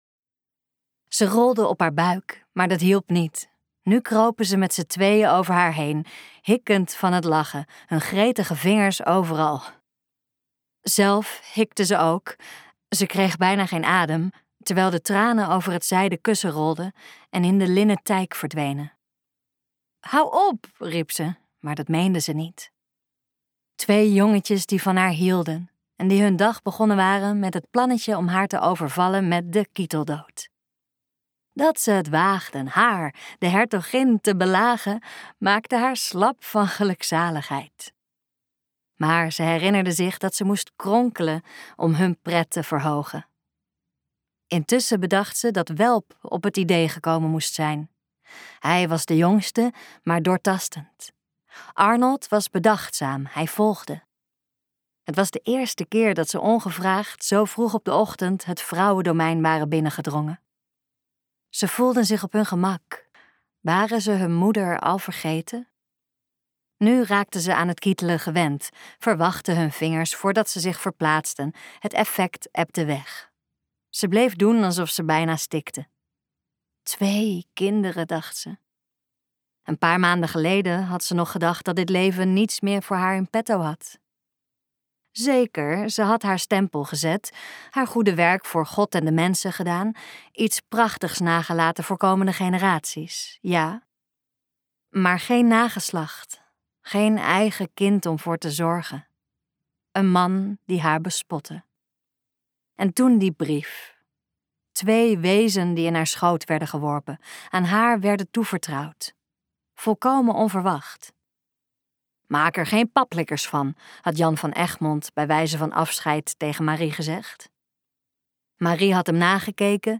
Ambo|Anthos uitgevers - De pleegzoon luisterboek